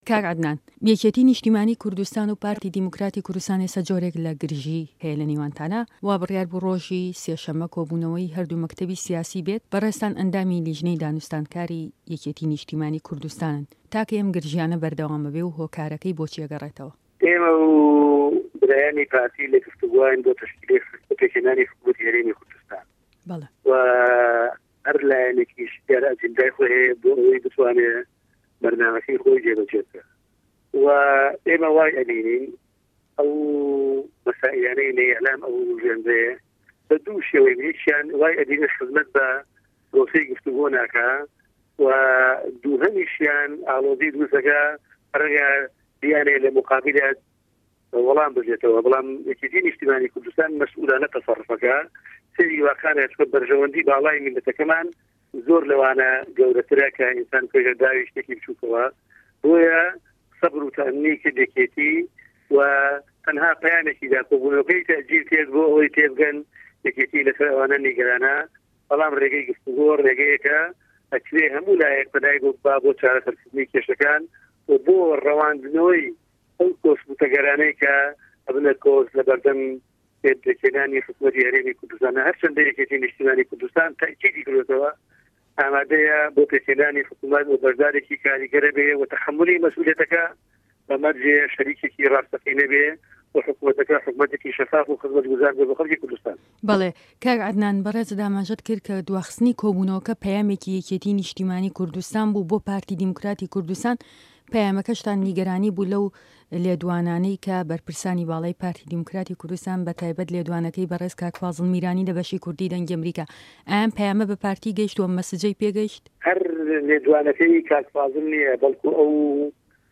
ده‌قی وتووێژه‌كه‌ی